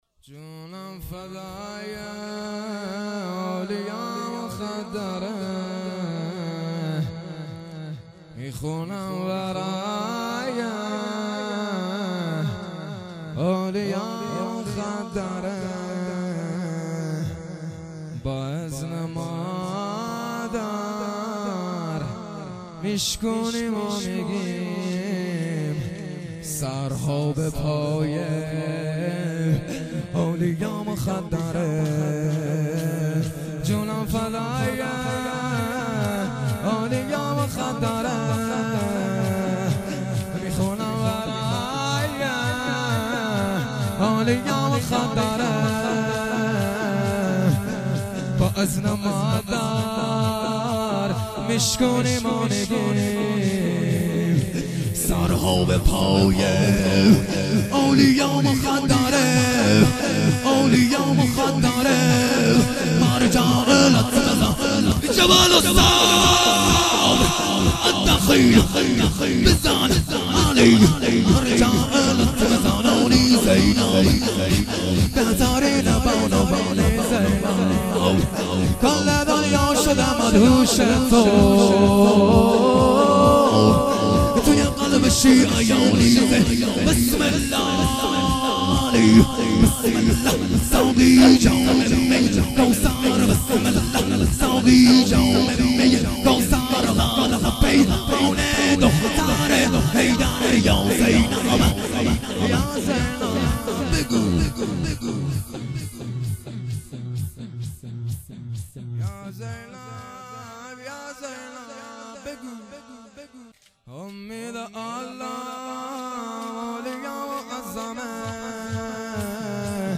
شور
شهادت امام صادق۱۳۹۸